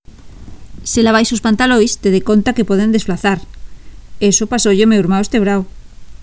3- Bloque oriental
- Inexistencia de gheada.
- Ausencia de seseo